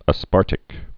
(ə-spärtĭk)